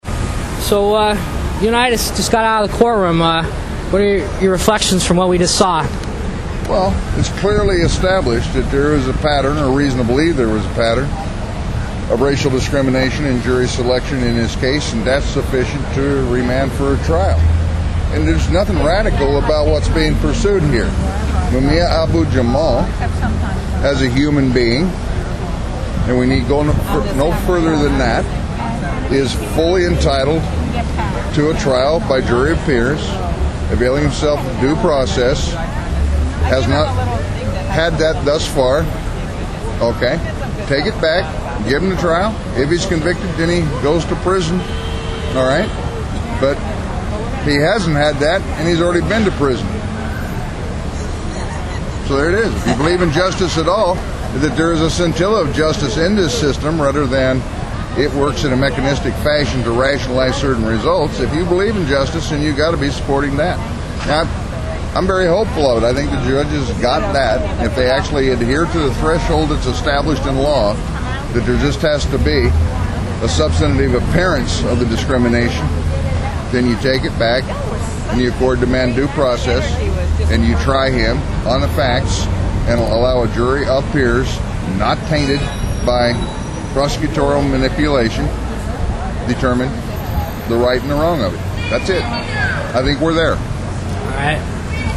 RADIO INTERVIEWS with observers of Mumia's hearing today